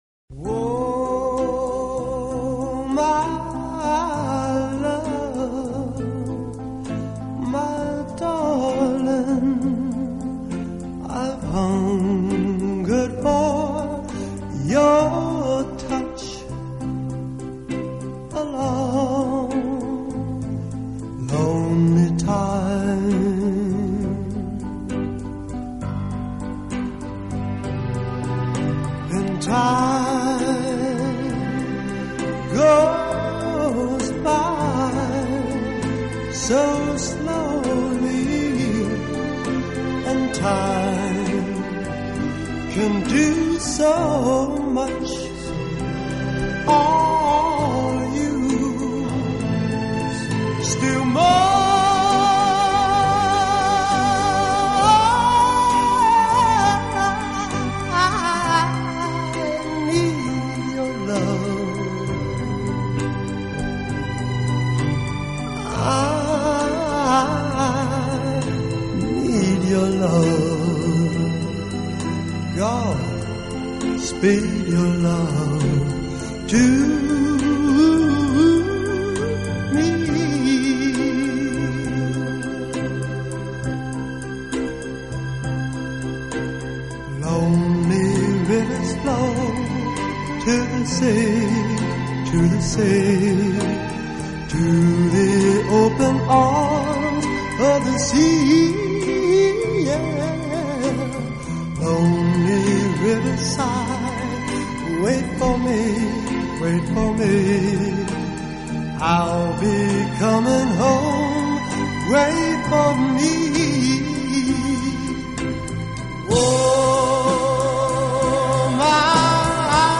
当您在聆听浪漫音乐的时候，优美，舒缓的音乐流水一样缓缓抚过心田，你会觉